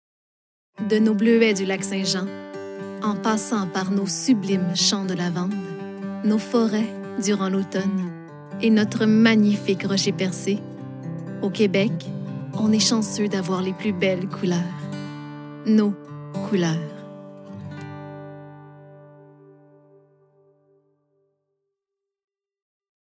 Âge naturelle de la voix Jeune adulte
Timbre Aigü - Claire
Composition vocale Chaleureuse - Inspirante - Posée - Sincère - Douce
Sico - Inspirante - Enveloppante - Québécois Naturel /
Annonceuse - Fictif 2024 00:23 545 Ko